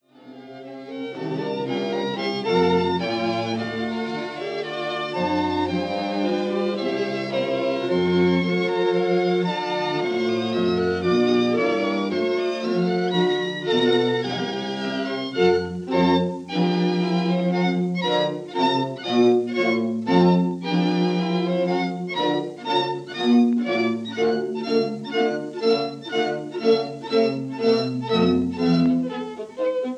1st Violin
Cello
recorded this quintet in 1936 at Londons Abbey Road Studio.